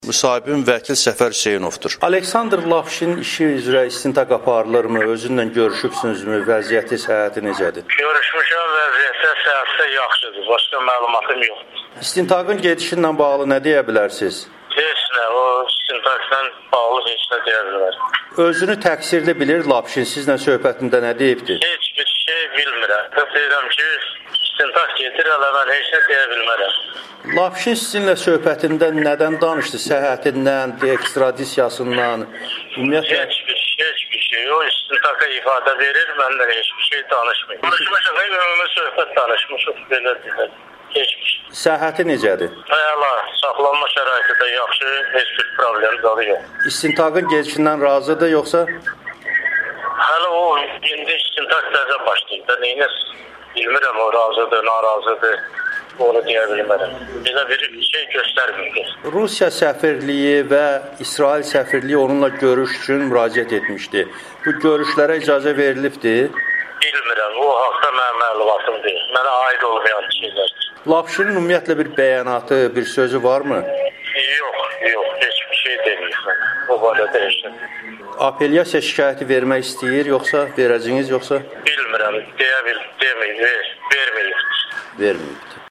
[Audio-müsahibə]